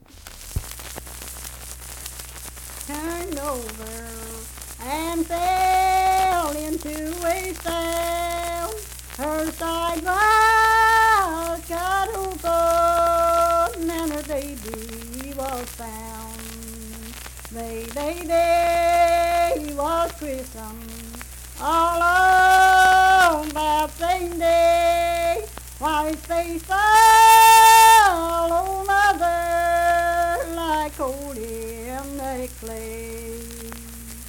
Unaccompanied vocal music performance
Verse-refrain, 6(4).
Voice (sung)